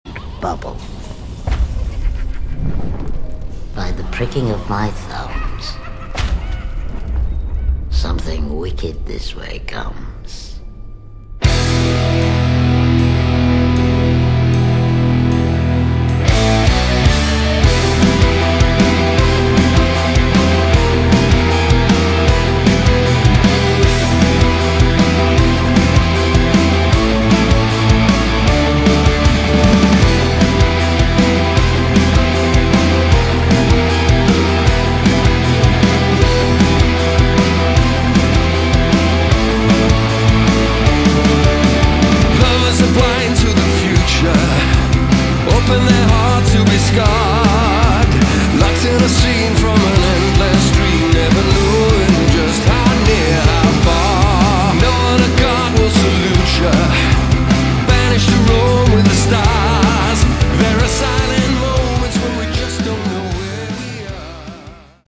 Category: AOR
guitars
keyboards
drums
backing vocals